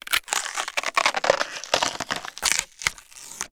ALIEN_Insect_02_mono.wav